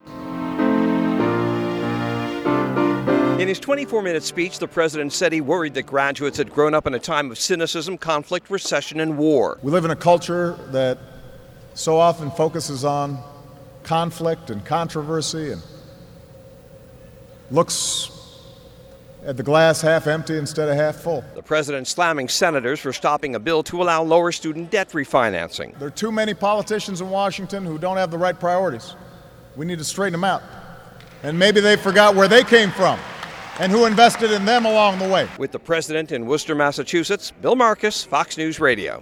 IN A COMMENCEMENT SPEECH AT WORCESTER TECHNICAL HIGH SCHOOL PRESIDENT OBAMA PRAISED A TECHNICAL SCHOOL THAT HAD TURNED ITSELF AROUND AND STUDENTS WHO HE SAID WERE ALREADY GIVING BACK TO THE COMMUNITY.